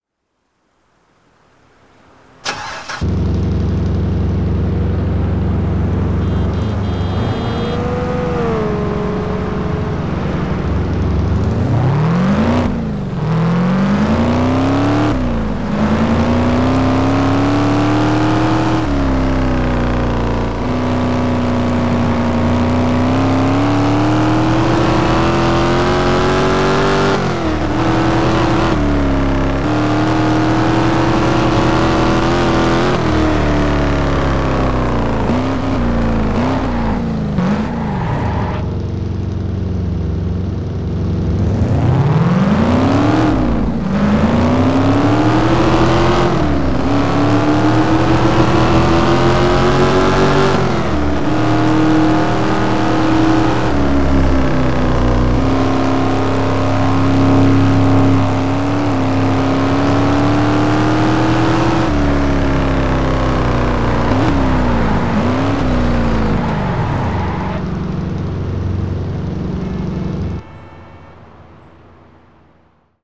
- Chevrolet Camaro SS